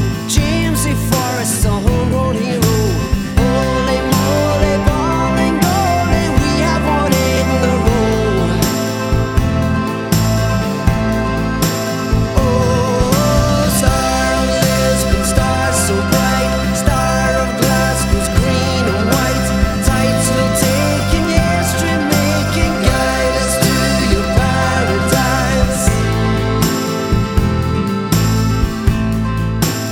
• Christmas